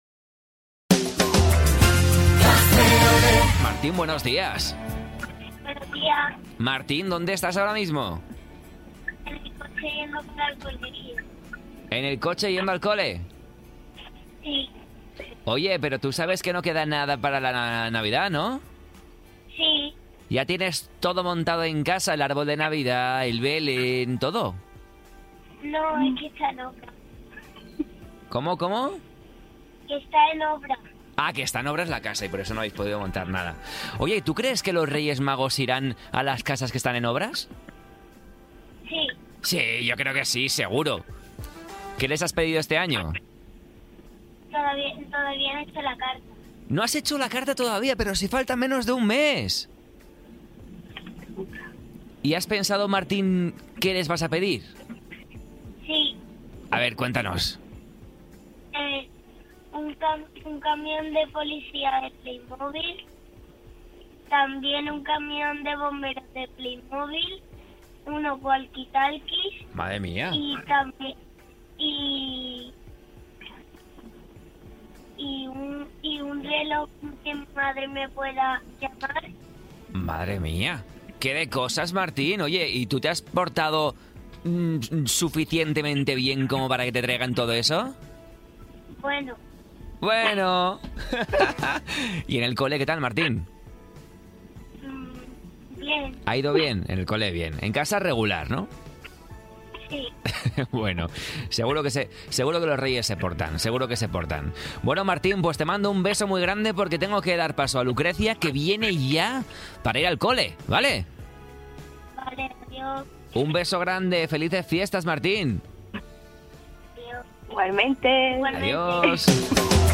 Un ‘cocodrilo’ que se está preparando para la Navidad llama en directo